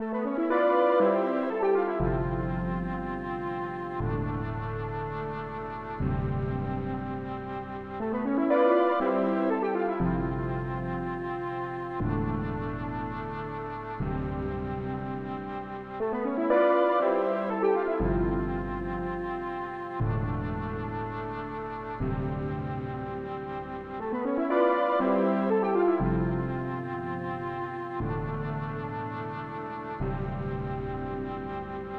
Piano & Flöte im leeren Raum soll professionell klingen - wie?
Piano kommt aus der RetroHead Bank vom Synth1 und Flöte ist die Mellotronflöte von Artifake_Labs. Iss jetzt pur ohne irgendwas. Soll auch kein gesampletes Piano sein.